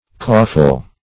caufle - definition of caufle - synonyms, pronunciation, spelling from Free Dictionary
caufle - definition of caufle - synonyms, pronunciation, spelling from Free Dictionary Search Result for " caufle" : The Collaborative International Dictionary of English v.0.48: Caufle \Cau"fle\, n. A gang of slaves.